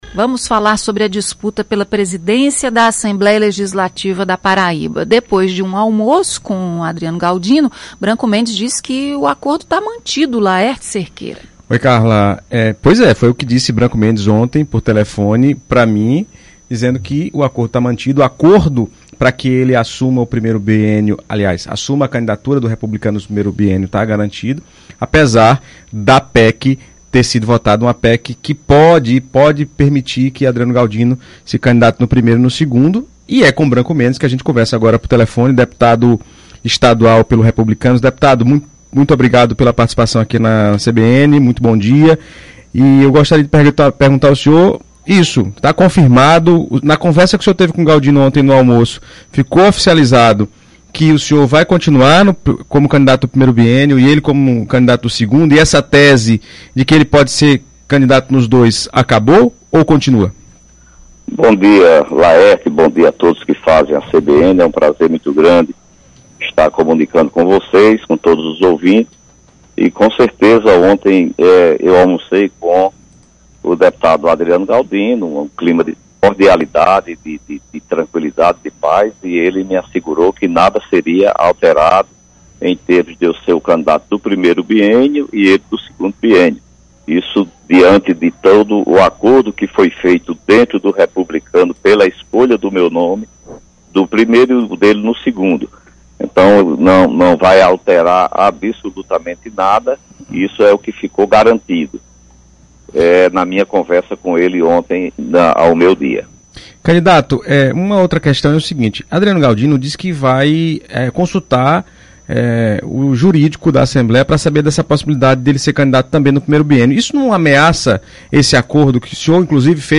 A confiança de que tudo sairá como planejado foi reafirmada em entrevista à CBN Paraíba, na manhã desta quinta-feira (22).
ENTREVISTA-BRANCO-MENDES.mp3